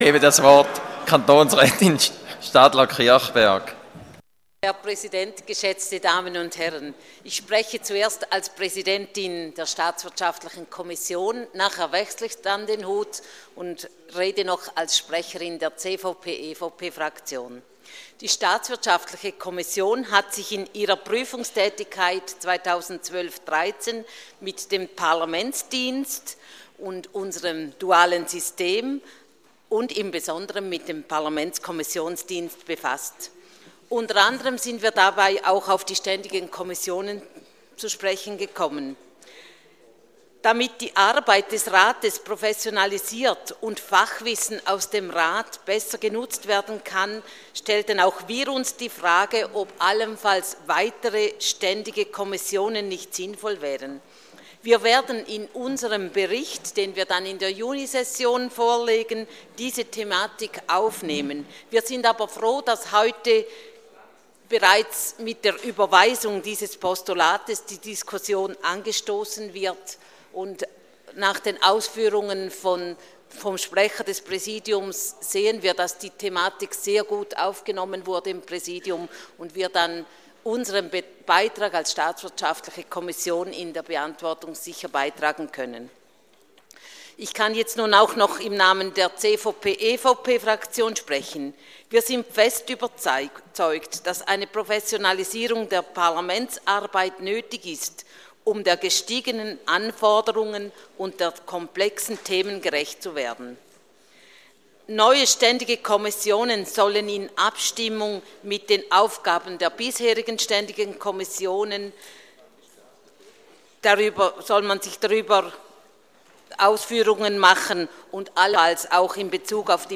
27.2.2013Wortmeldung
Session des Kantonsrates vom 25. bis 27. Februar 2013